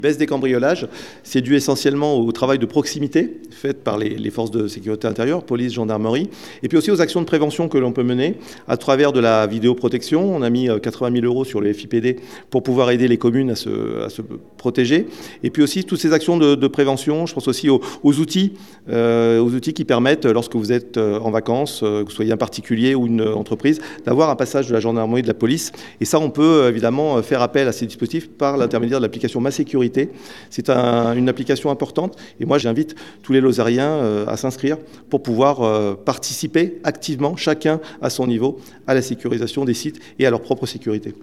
« Une délinquance maîtrisée » c’est avec ces mots que le préfet Gilles Quénéhervé a commencé sa prise de paroles face à la presse, jeudi dernier, pour tirer le bilan de la sécurité en Lozère en 2024.
Sur le chapitre de l’atteinte aux biens, les chiffres sont au vert : -23 % de cambriolages en 2024 par rapport à 2023. Une baisse notable qu’explique le préfet.